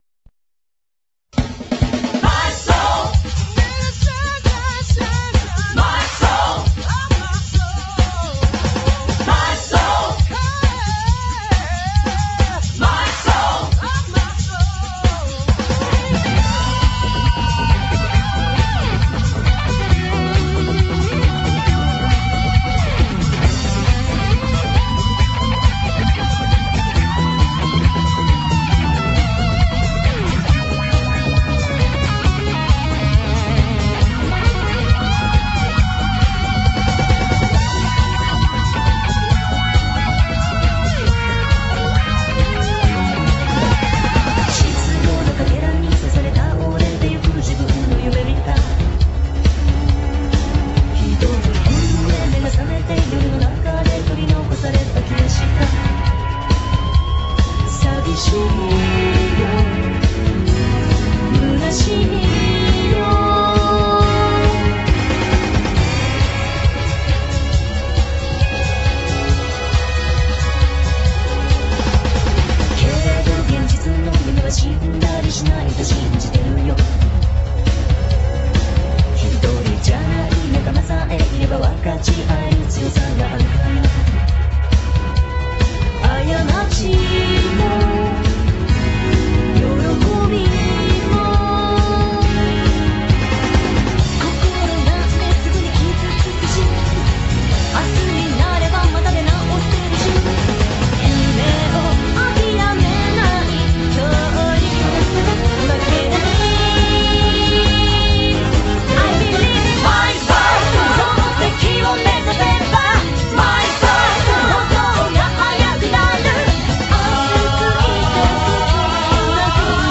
(Fireball Groove Mix)